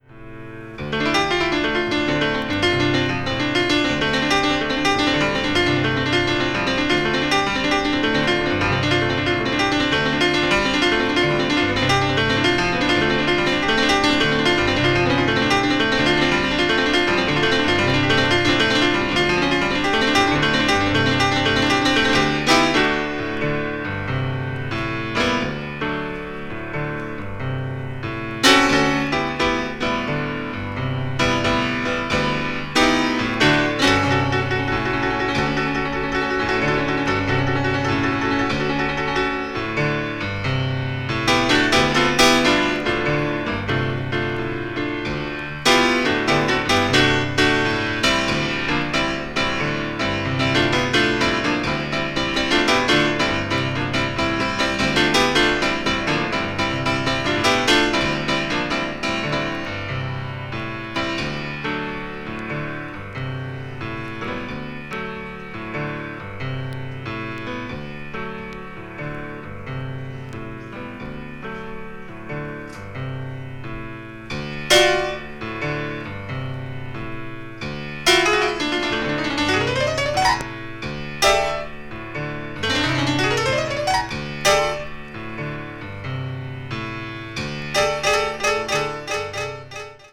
media : EX/EX(some slightly noise.)
avant-jazz   contemporary jazz   free jazz   spiritual jazz